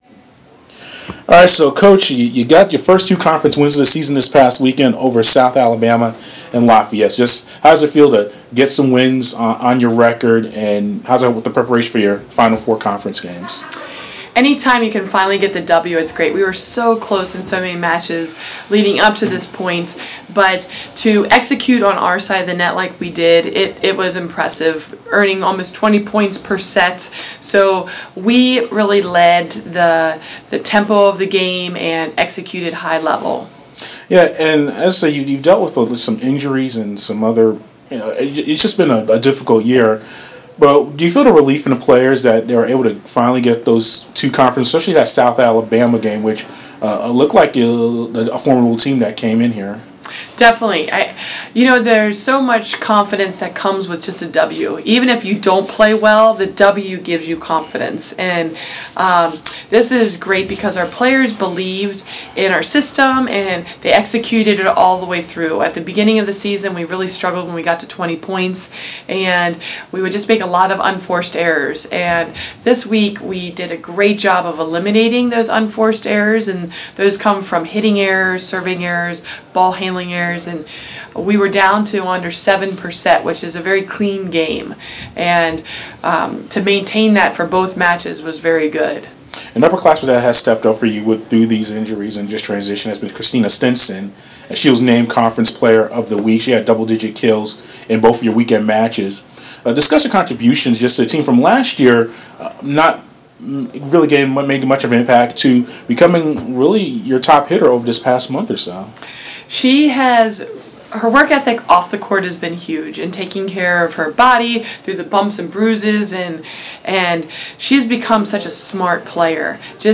Fighting Peaches: Interview